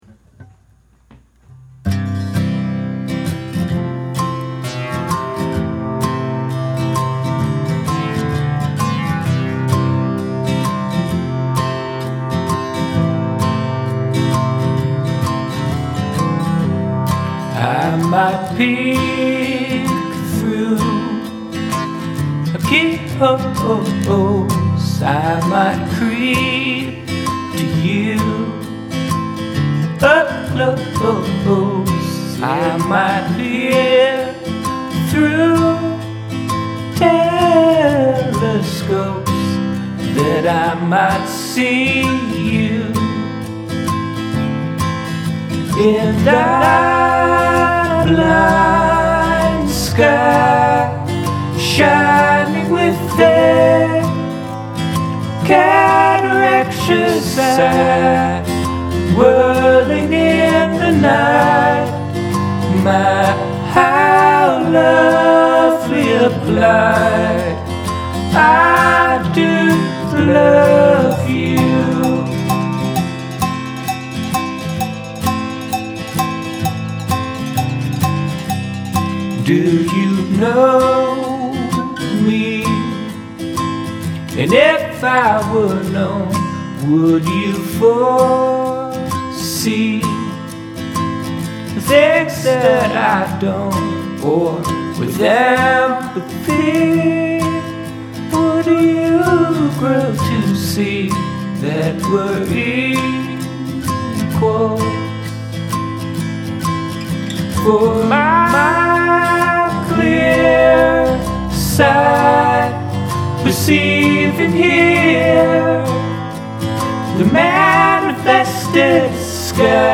verse: C, Fmaj7, Am (x4)
chorus: F, C, G, Am, F, G, Am, F, C, G, C
i'm really liking this song now. i came up with the verse and chorus melody and chords together. to me they sounded pretty joyful, i wasn't sure what to write about. as i was taking a walk i started thinking of the sky as a giant eye, but one that was blinded, from there the rest of the chorus wrote it self. the verses came without too much trouble. when i first recorded i had lots of fuzzy distorted guitars on the chorus, but then i dropped them in favor of the low harmony which works much better. i'd like to do a cleaner recording of the vocals. my annunciation is awful and the three tracks of vocals aren't lined up well in the chorus section especially. in the verse parts, i'm saying slightly different words on the different tracks. kind of a sloppy job all around --which is too bad since i'm proud of the words here. especially "my how lovely a blight". i don't know about the dance beat at the end of the song yet. i like the idea of it, but i'm not sure its working. i think this would a good first song on an EP.
I haven't even been able to focus on the lyrics because the melodies and harmonies on the chorus are so incredible. The production with the assorted go-go bells is really fun, too, and keeps the feel lively.